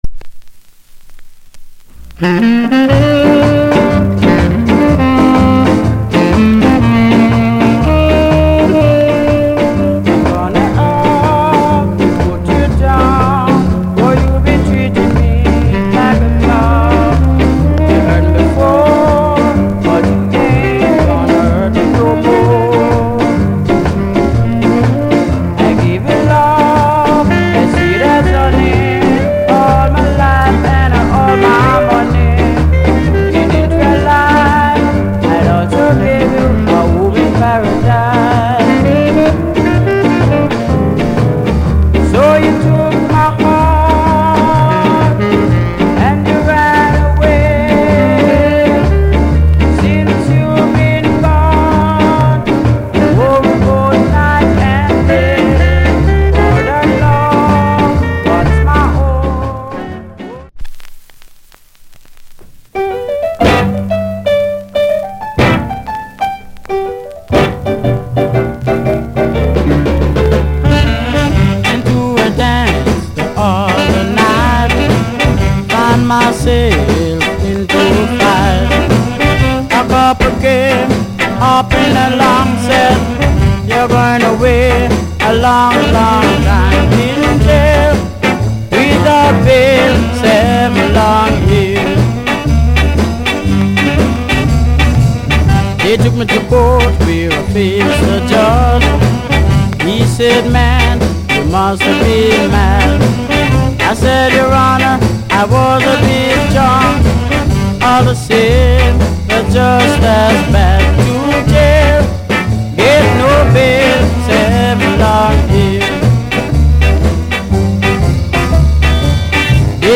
Killer Shuffle on Both Side !!!